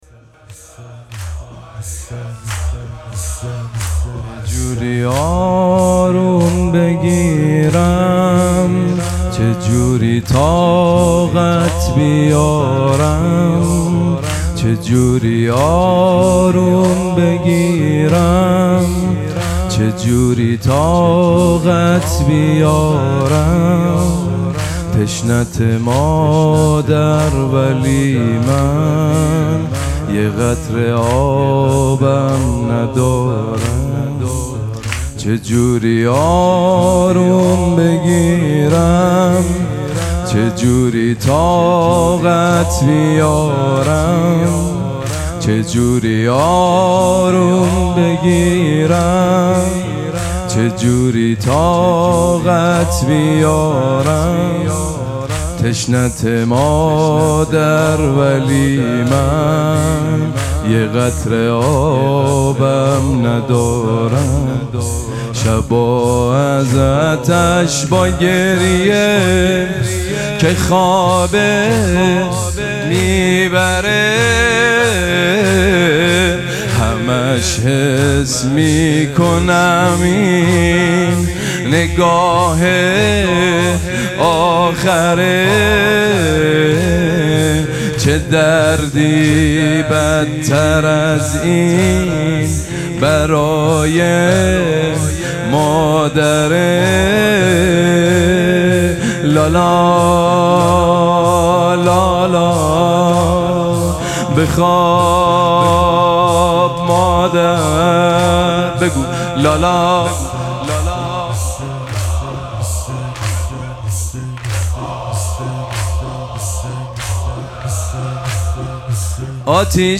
مراسم مناجات شب دوازدهم ماه مبارک رمضان
حسینیه ریحانه الحسین سلام الله علیها